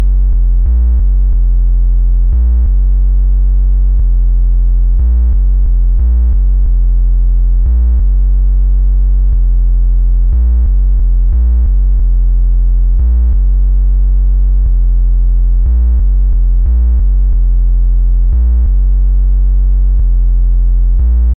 描述：低柔韧的贝斯声 嘻哈循环
Tag: 94 bpm Hip Hop Loops Bass Loops 1.72 MB wav Key : Unknown